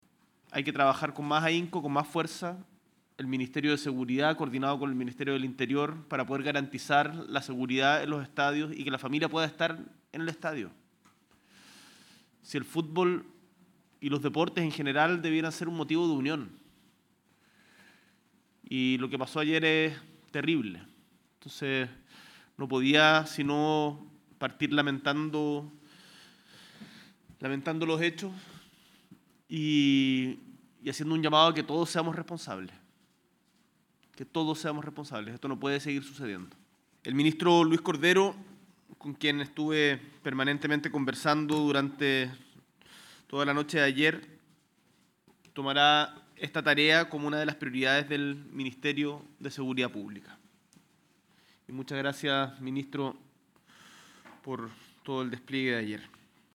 Este viernes a primera hora y antes de comenzar el consejo de gabinete en el Palacio de La Moneda, el Presidente Gabriel Boric se refirió a los hechos ocurridos ayer jueves en el partido de Copa Libertadores entre Colo Colo y Fortaleza, donde dos personas resultaron fallecidas.